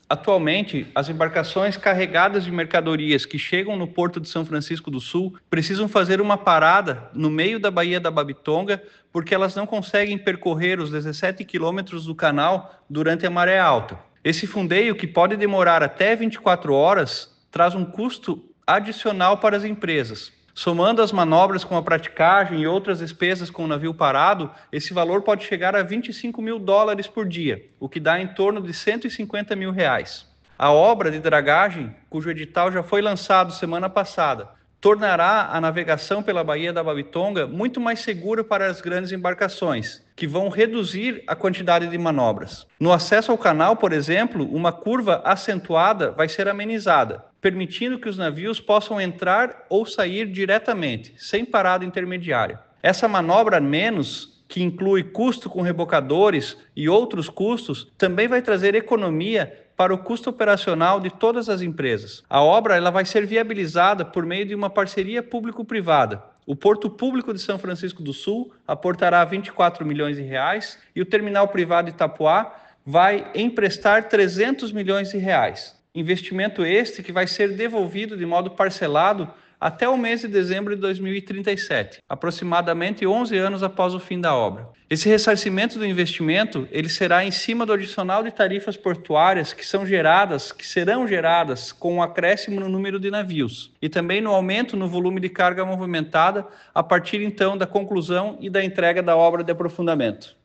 Para o presidente do Porto de São Francisco, Cleverton Vieira, a obra tornará a navegação pela Baía da Babitonga mais segura para as grandes embarcações, que reduzirão a quantidade de manobras:
SECOM-Sonora-Presidente-Porto-Sao-Francisco-do-Sul-Obra-Dragagem.mp3